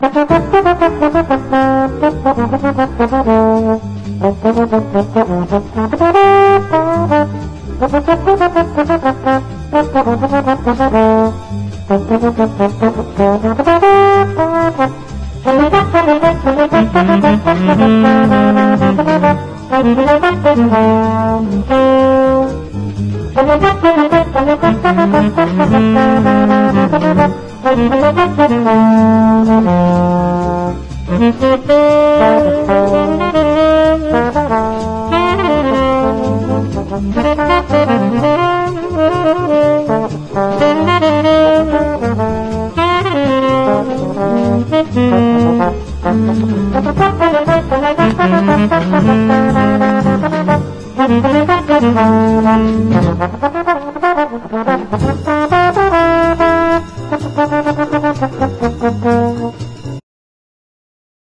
JAZZ / MAIN STREAM / BIG BAND JAZZ
洒脱なモダン・スウィング